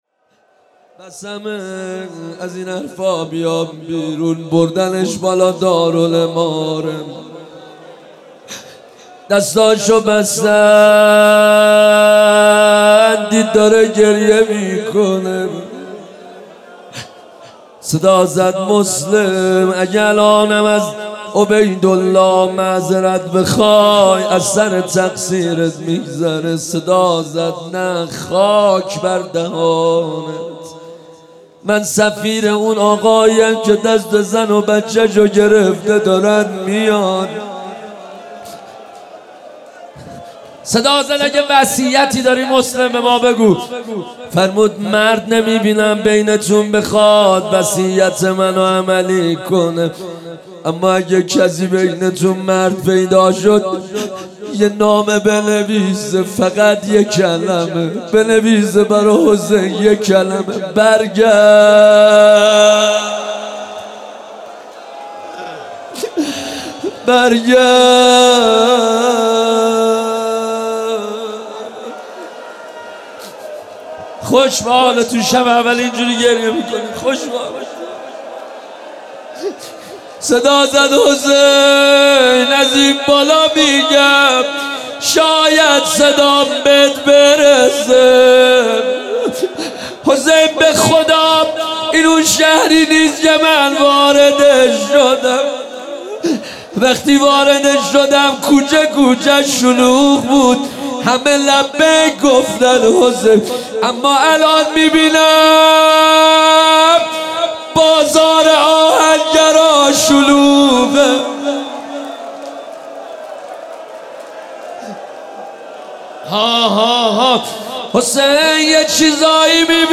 مناسبت : شب اول محرم
قالب : روضه